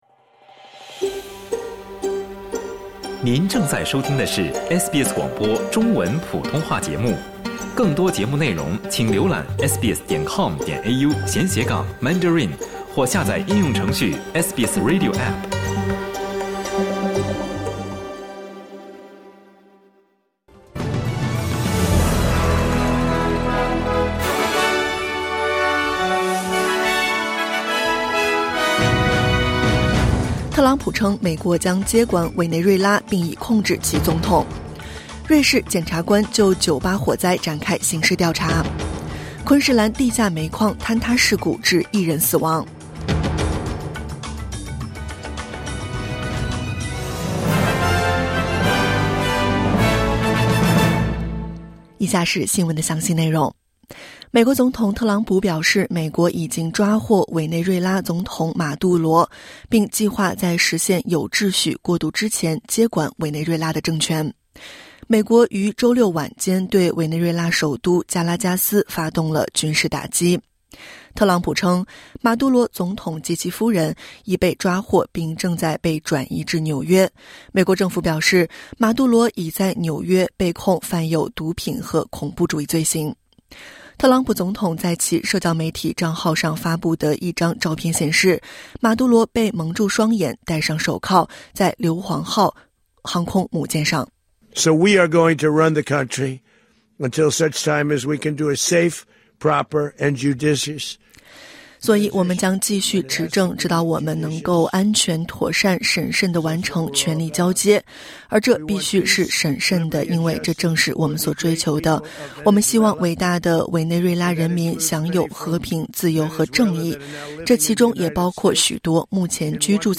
【SBS早新闻】特朗普称美国将接管委内瑞拉并已控制其总统